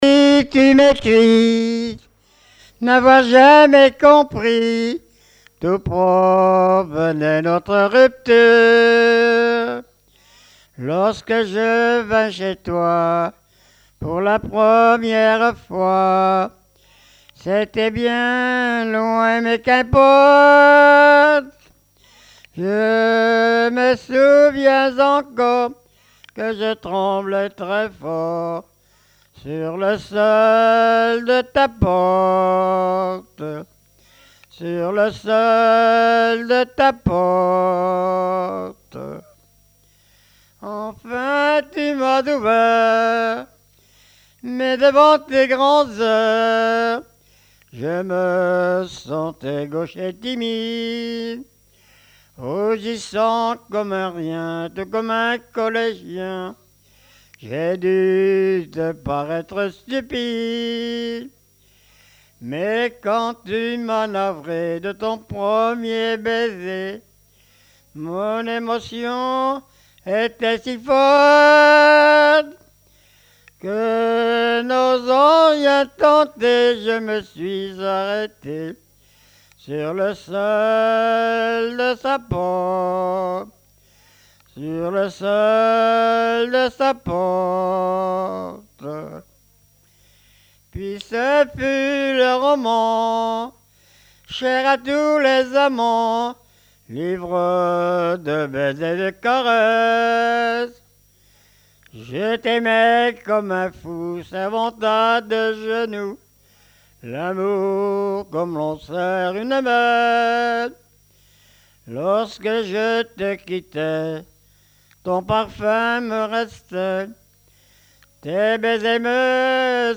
Genre strophique
recueil de chansons populaires
Pièce musicale inédite